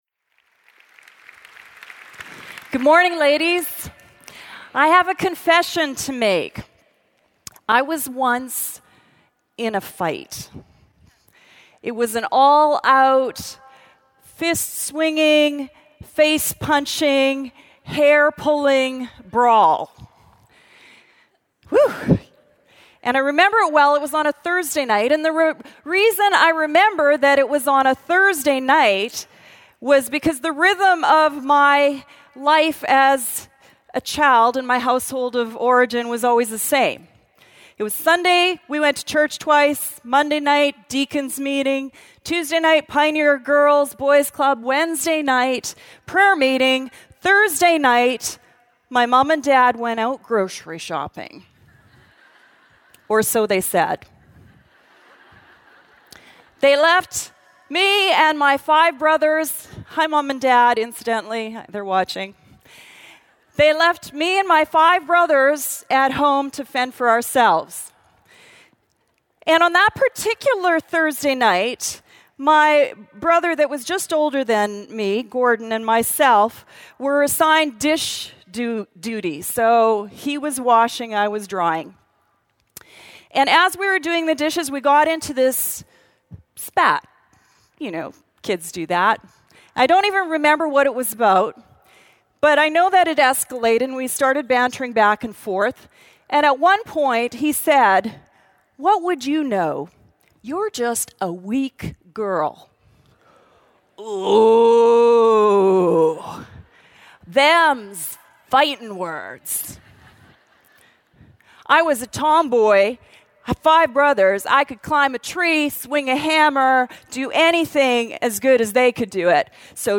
Don't Be a Wimp: Kicking the Habits That Make Women Weak | True Woman '14 | Events | Revive Our Hearts